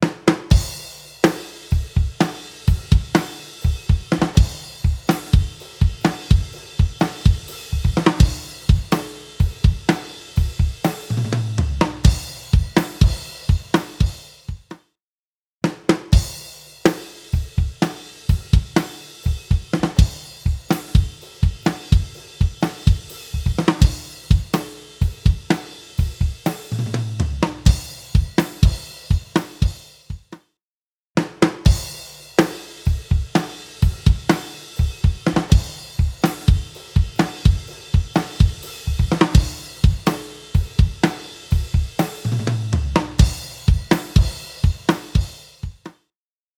Invigorate | Drums | Preset: Bottom End Leveler
Invigorate-Drums-Bottom-End-Leveler-CB.mp3